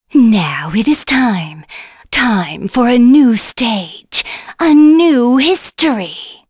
Shamilla-Vox_Skill6.wav